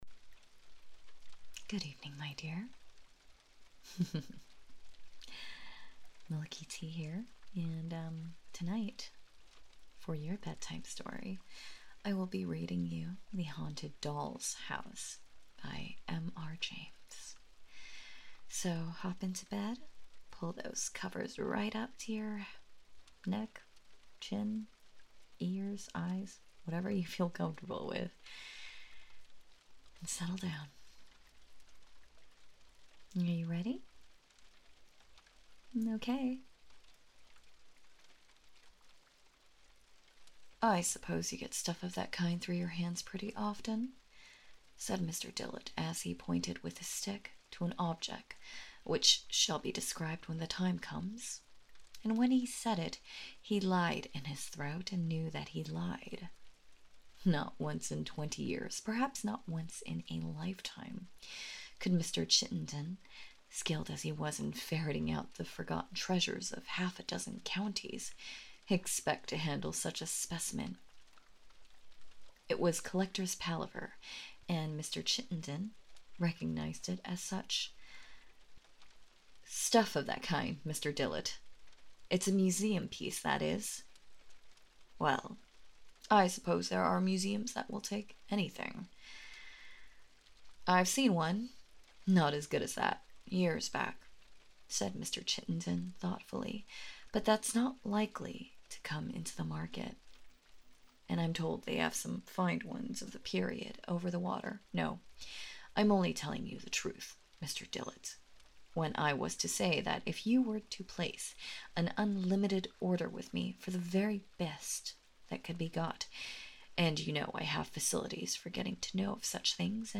Third time recording with 3 different stories...and the editing was such a mess, I'm so sorry!
For a bedtime story, I actually really like the unedited nature of this. The breaths and the needing to say a word again to pronounce it right, makes it seem like you're actually reading to us rather than listening to a script.